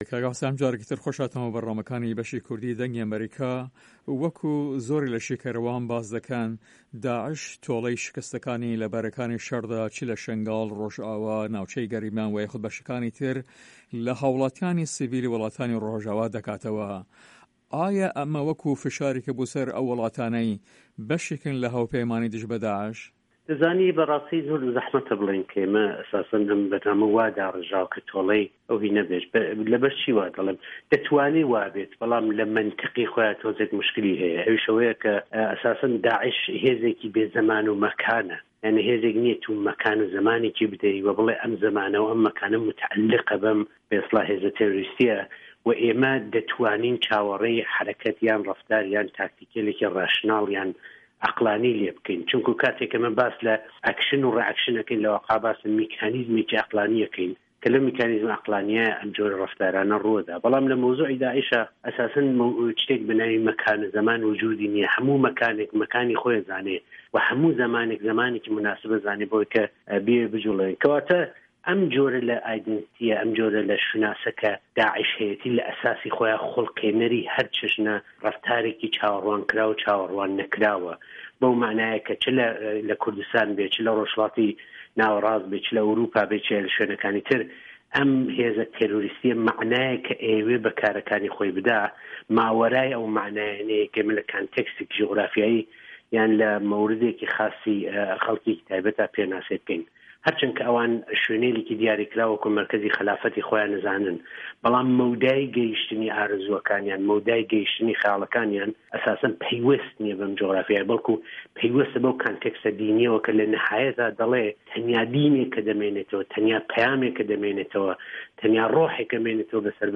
هەڤپەیڤینێکدا